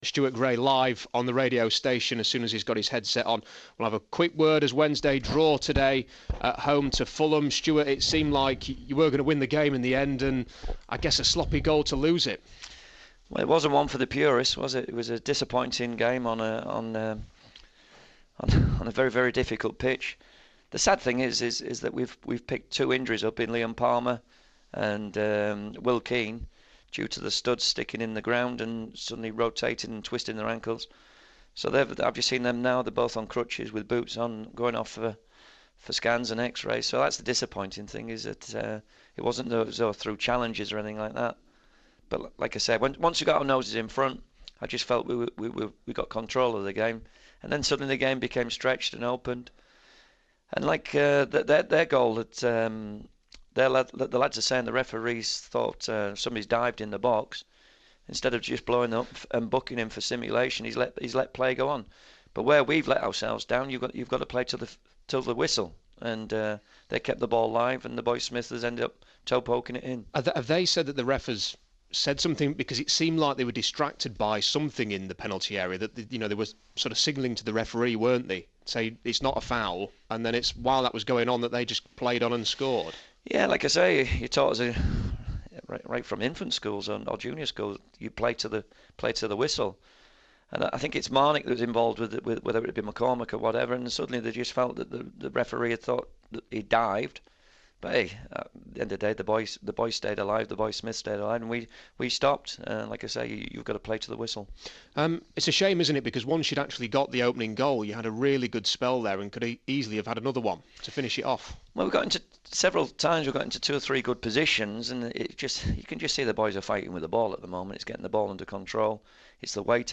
INTERVIEW: Sheffield Wednesday Head Coach Stuart Gray after his sides 1-1 draw with Fulham